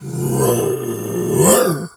pgs/Assets/Audio/Animal_Impersonations/bear_roar_02.wav at master
bear_roar_02.wav